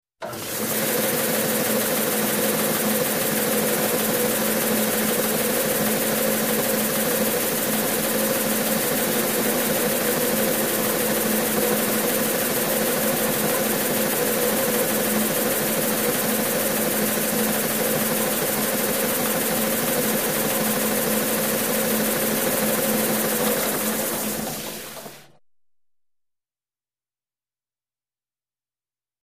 SanderToolOnStdOff PE084001
Motorized Shop Tool, Sander ( Belt ); Turn On Steady, And Off With Short Wind Down.